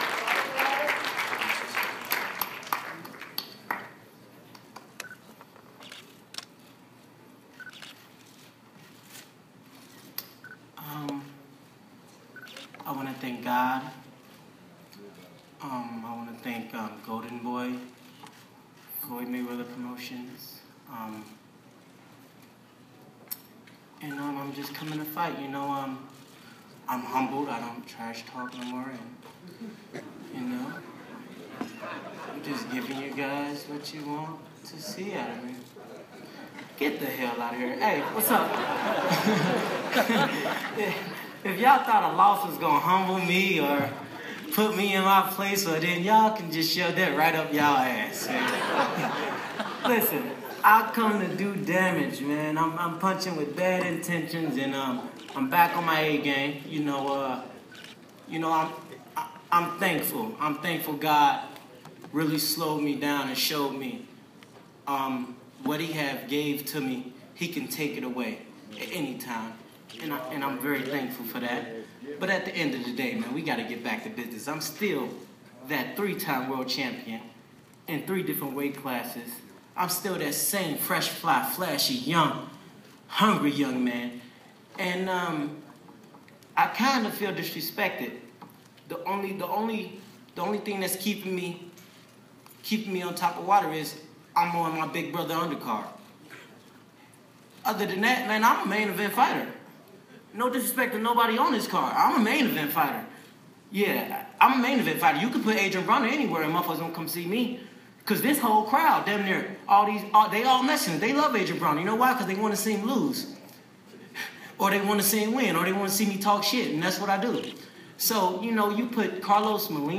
[Photos] Mayweather-Maidana Final Undercard Press Conference Recap: Broner Calls Molina Fight “Disrespectful”
LAS VEGAS — The final undercard press conference just wrapped for the Mayweather-Maidana undercard with former titlist Adrien Broner stealing the show with declarations that he’s a main event fighter who’s taken his Carlos Molina comeback fight as “disrespectful.”